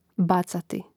bàcati bacati